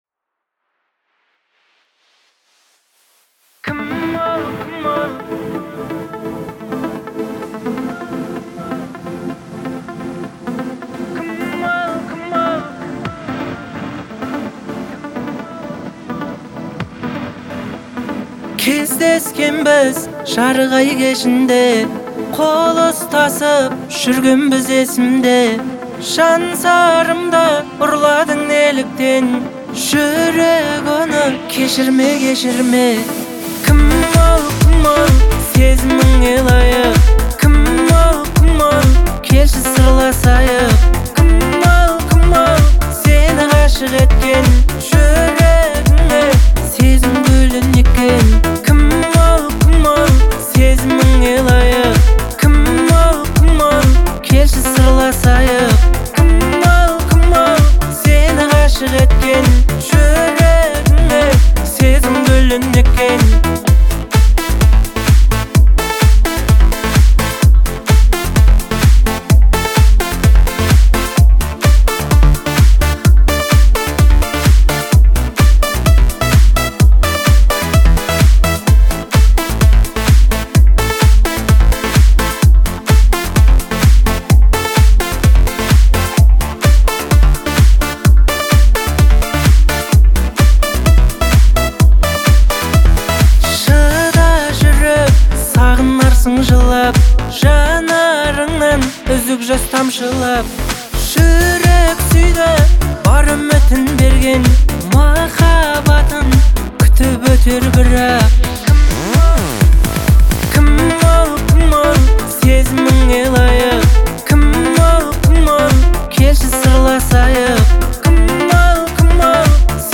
это яркий образец казахской поп-музыки